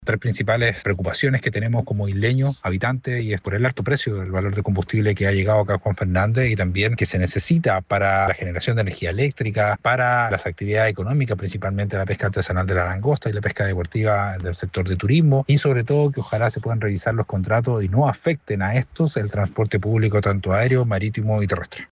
Por su parte, el alcalde de Juan Fernández, Pablo Manríquez, señaló que el reciente arribo de abastecimiento ya evidenció precios más altos en combustible, gas y alimentos, anticipando un impacto mayor en el costo de vida local y en actividades económicas clave como la pesca y el turismo.